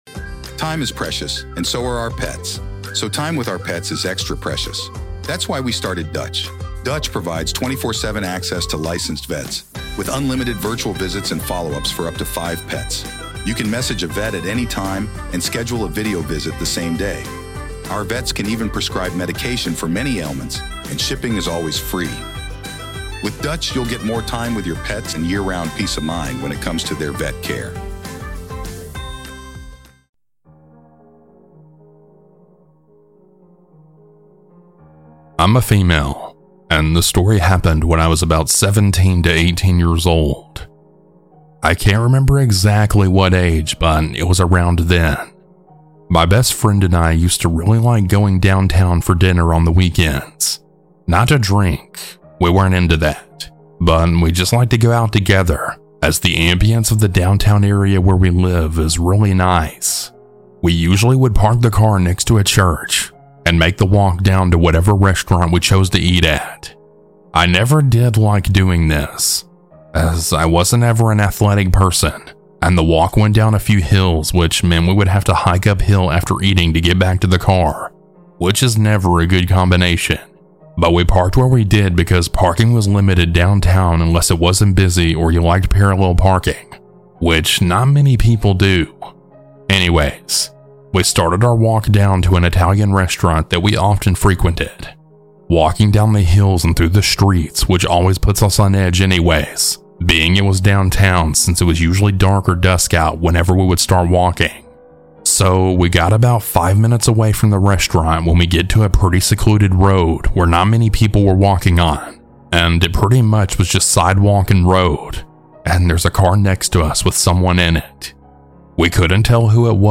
Huge Thanks to these talented folks for their creepy music!